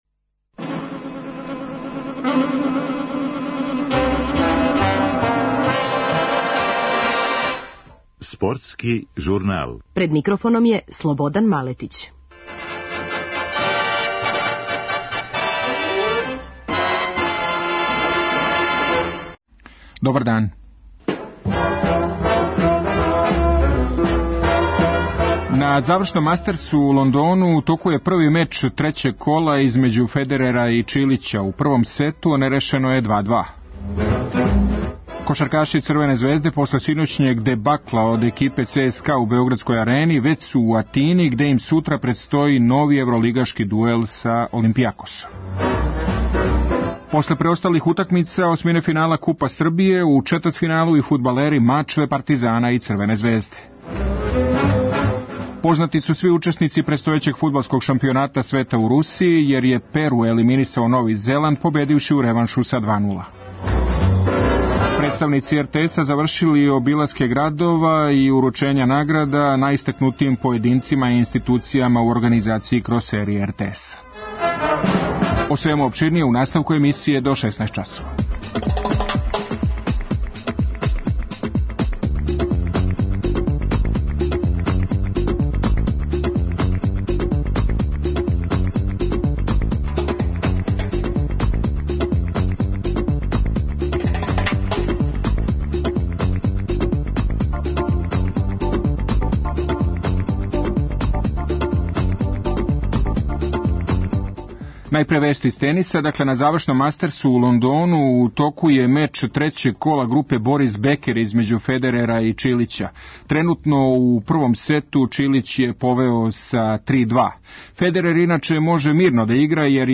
Кошаркаши ЦСКА лако победили Црвену звезду - чућемо изјаве актера Евролигашке утакмице која је одиграна у Београду.